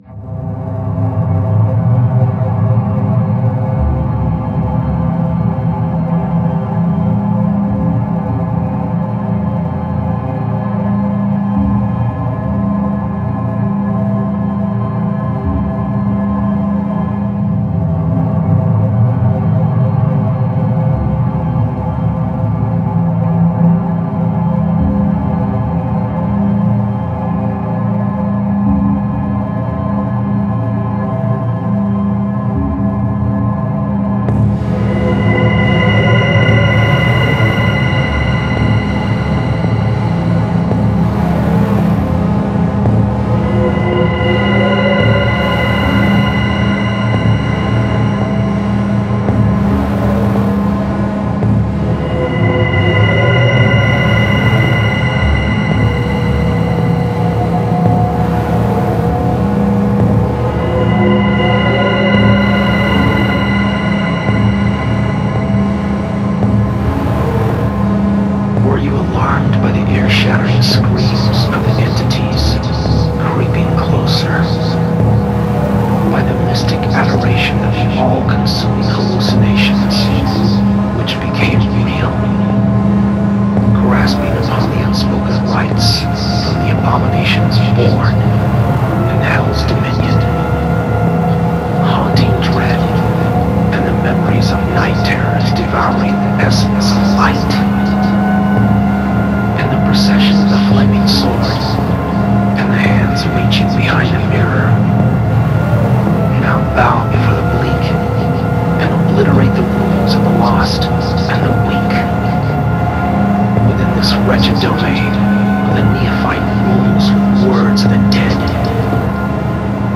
Dark Ambient